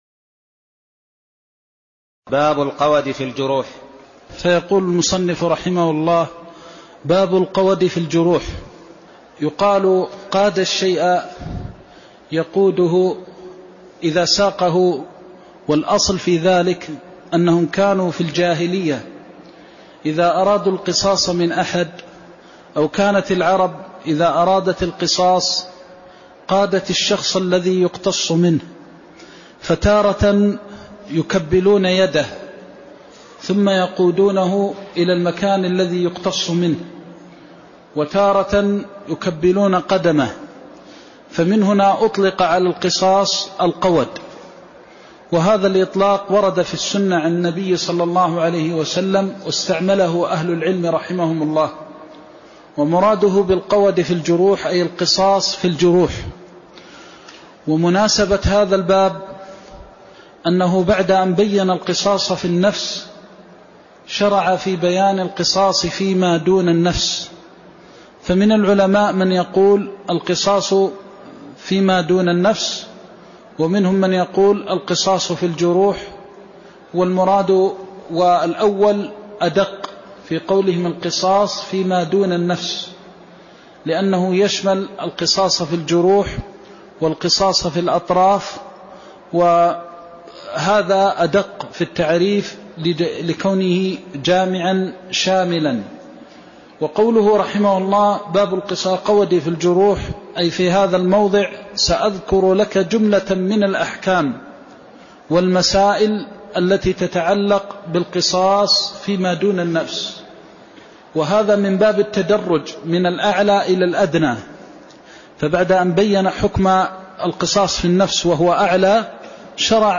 المكان: المسجد النبوي الشيخ: فضيلة الشيخ د. محمد بن محمد المختار فضيلة الشيخ د. محمد بن محمد المختار باب القود في الجروح (04)